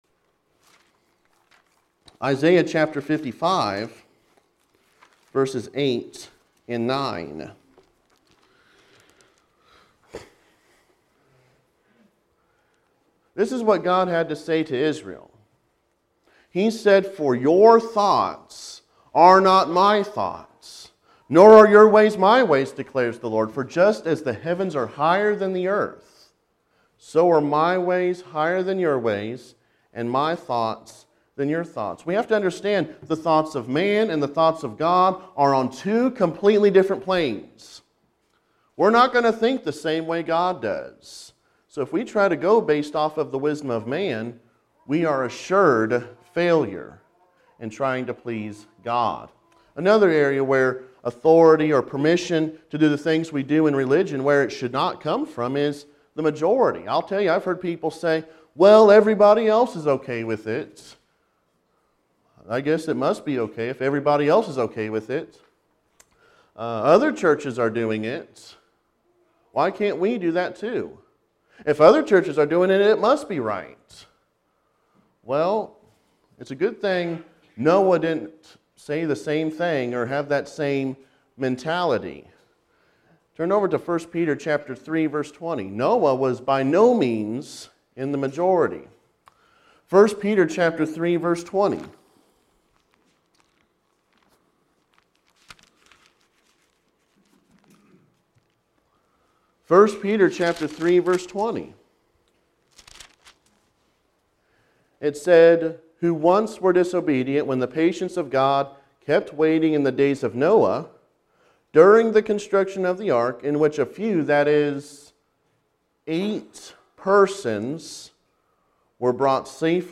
Service: Gospel Meeting